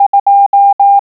Click on a letter, number, or punctuation mark to hear it in Morse code.